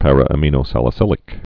(părə-ə-mēnō-sălĭ-sĭlĭk, ămə-nō-)